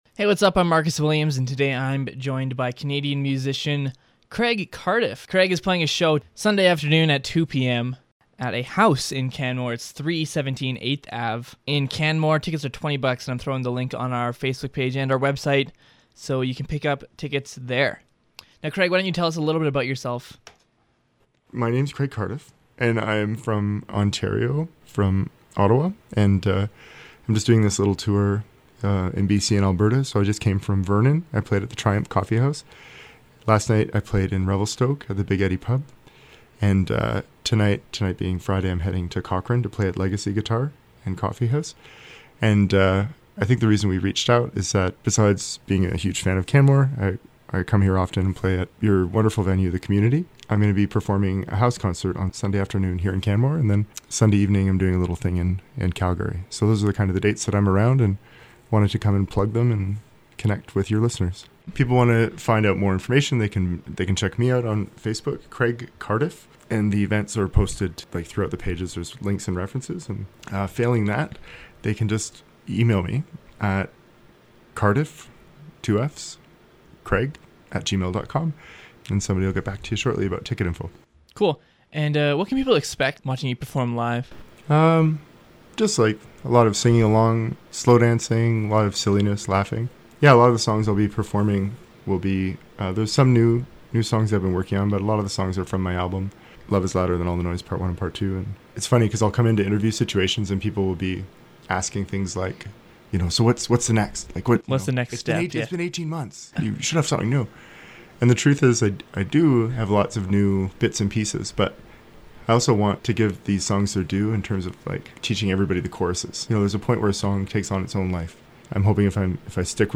popped in the studio for a quick chat and he played us a song:)